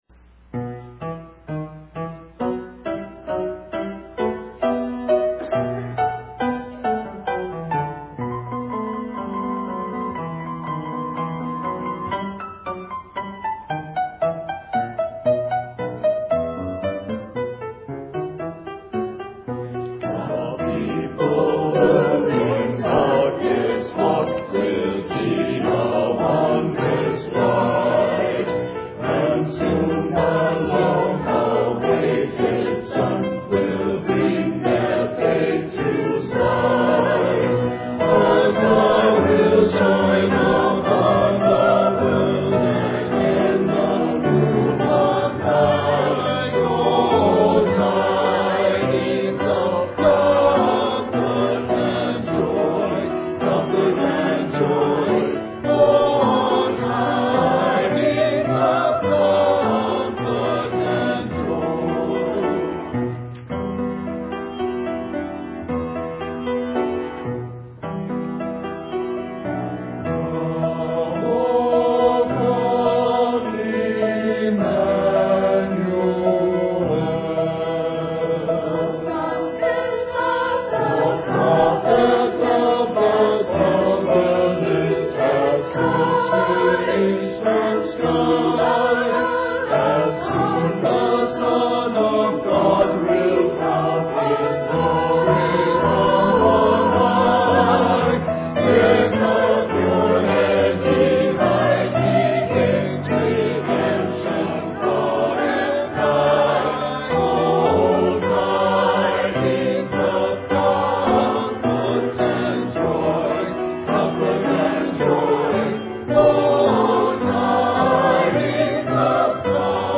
Sunday Service
Christmas Cantata